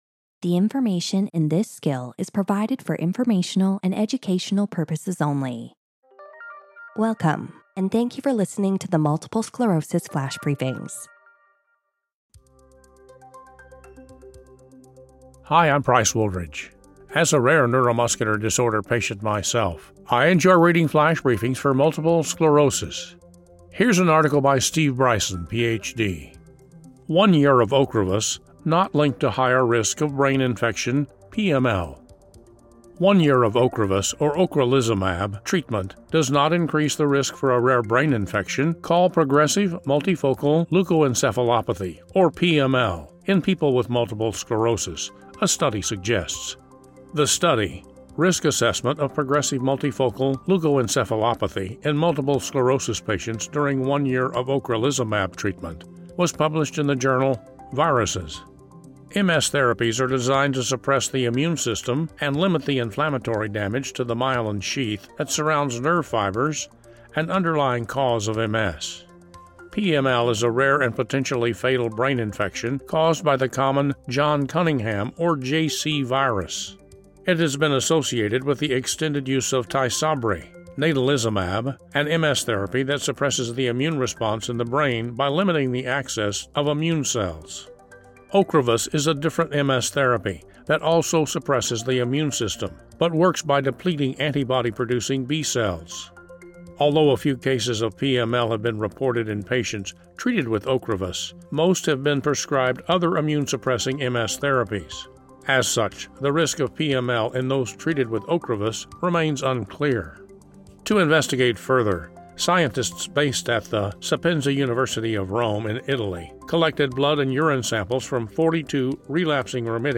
reads an article about how one year of Ocrevus (ocrelizumab) treatment does not increase the risk of a rare brain infection known as PML in people with MS, a study suggests.